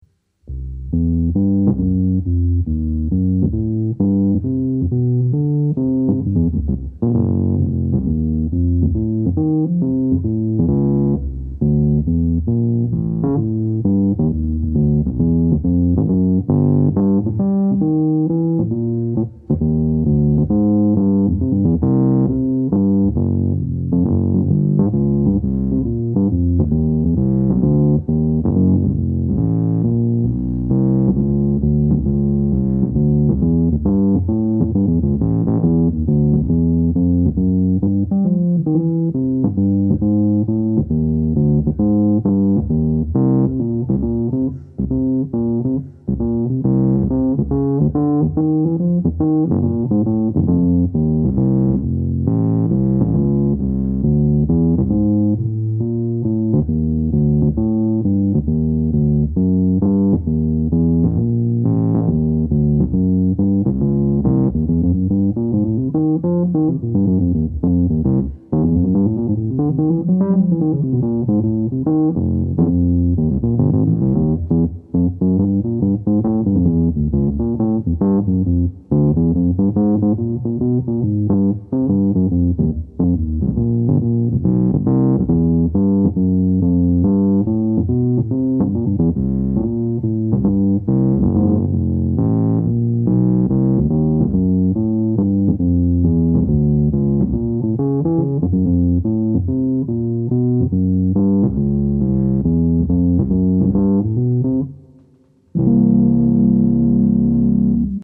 Mini RHODES BASS - Forum du son Hammond
Ca sonne monstrueux je mettrai un extrait.....gros gros son....je vais m'en servir pour le prochain album....certainement.
On reconnaît bien le grain du rhodes.
Belles basses bien pleines.
Avec un petit arrière goût de shooper (ou chopper, sais plus) dans l'infra grave, qui à l'air d'en faire un précieux auxiliaire.
Et joli walking de blues...
rhodesbass.mp3